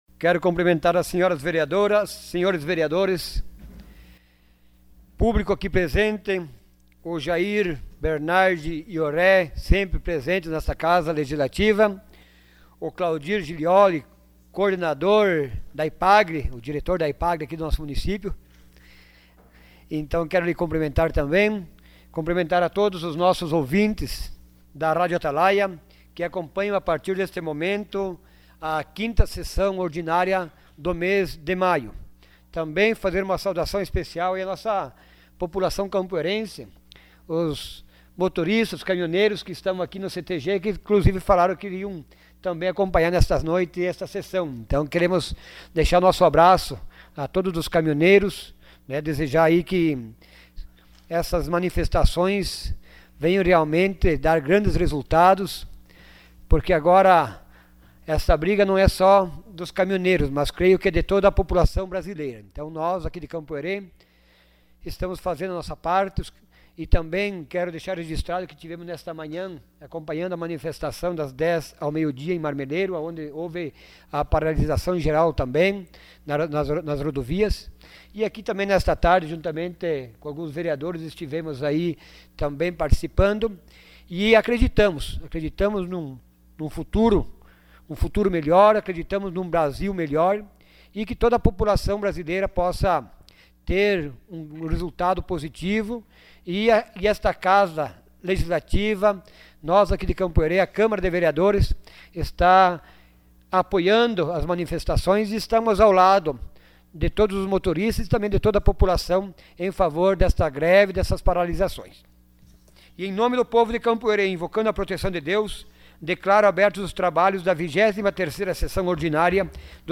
Sessão Ordinária dia 24 de maio de 2018.